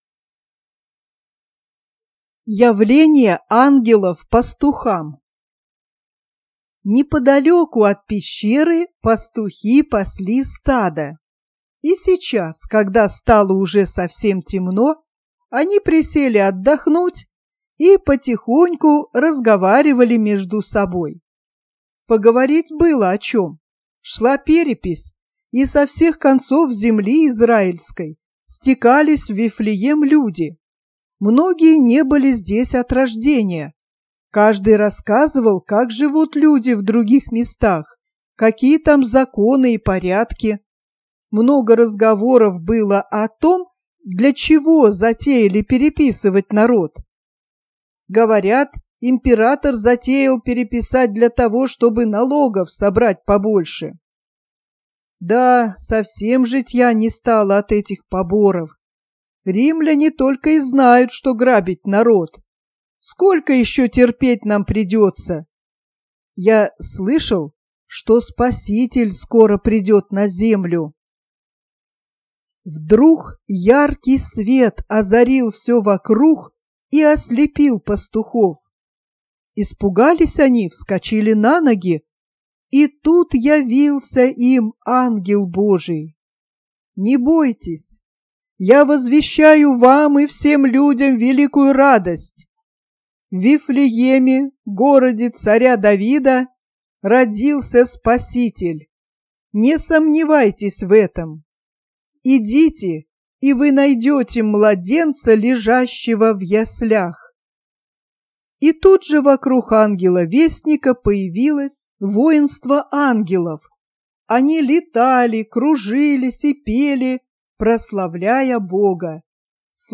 "ЯВЛЕНИЕ АНГЕЛОВ ПАСТУХАМ" - аудио рассказ о радости, узнавших о рождении Спасителя. 7 января - день Рождества Христова. Пастухам, пасшим неподалёку от пещеры овец явился ангел-вестник и сообщил радостную новость о рождении Младенца.